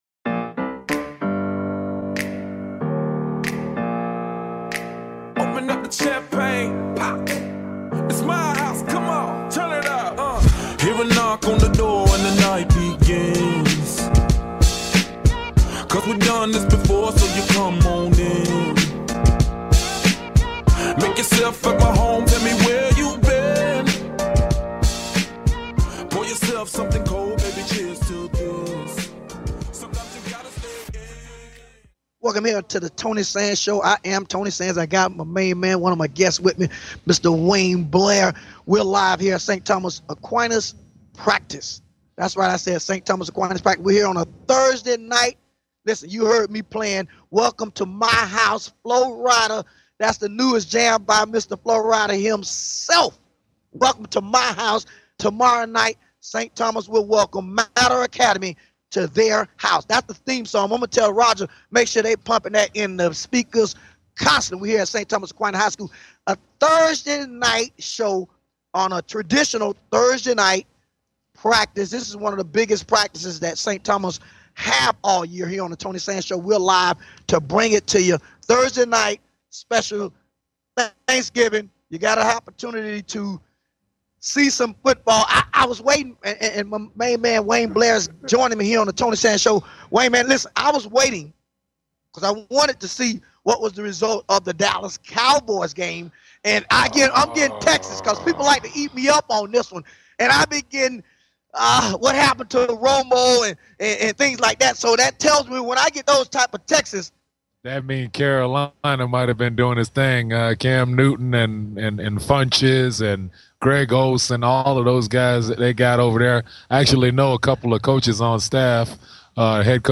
Talk Show Episode
at Tilt to Kilt Bar in South Florida - LIVE